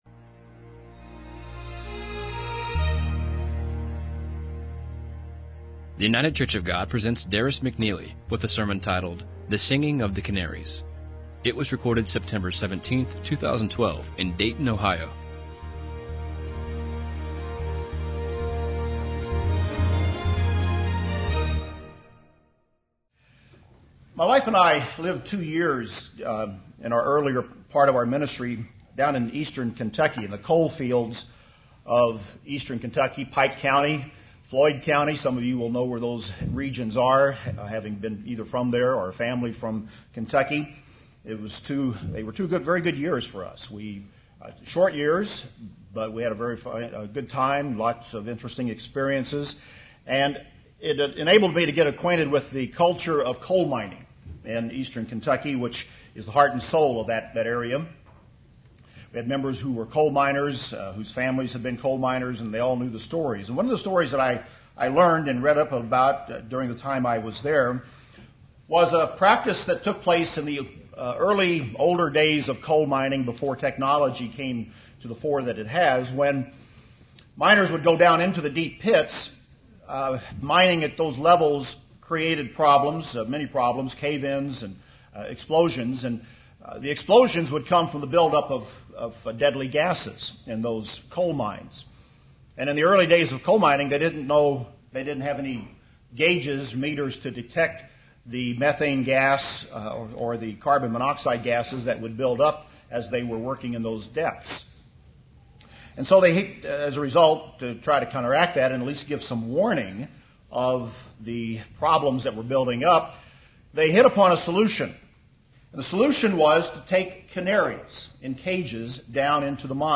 [ This sermon was given on the Feast of Trumpets ]